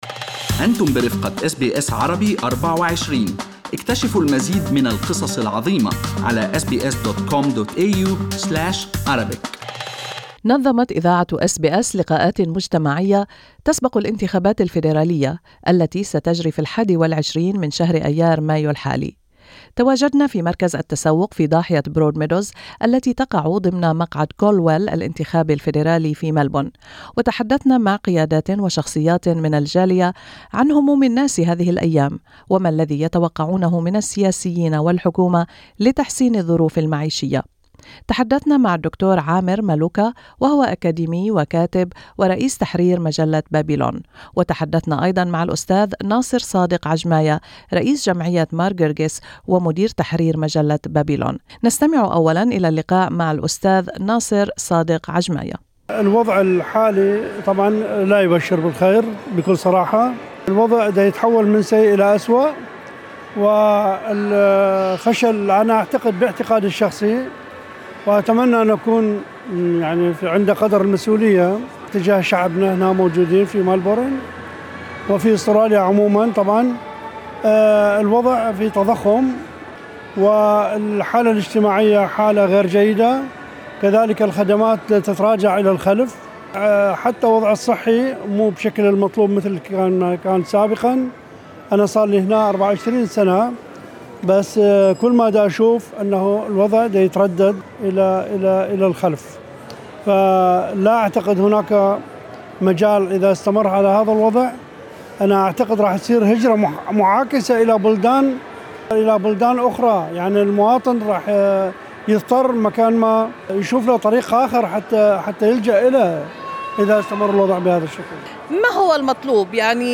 وفي الفعالية التي أقيمت في ضاحية برودميدوز أجرينا لقاءات مع قيادات وشخصيات في الجالية العربية.